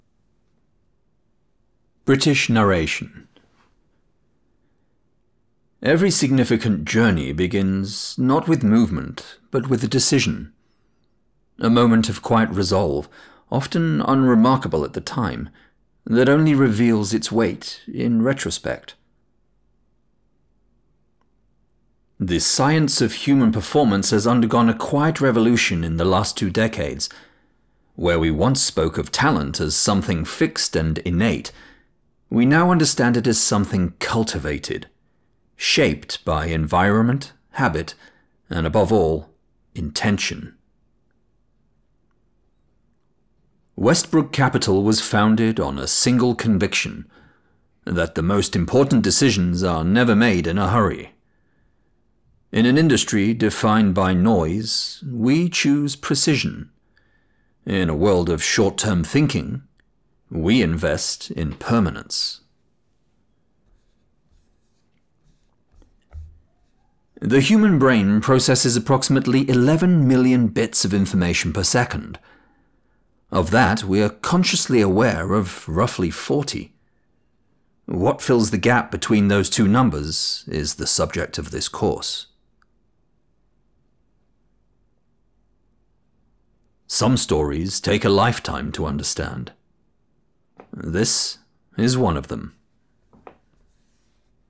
British Narration
Middle Aged
DEMO 2 — British Narration.mp3